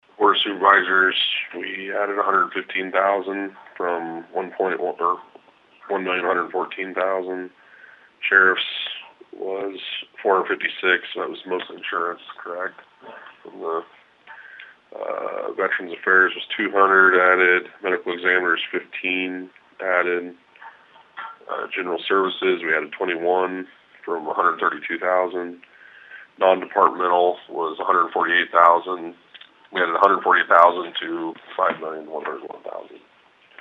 Board Chair Nathan Baier explained the appropriations resolution.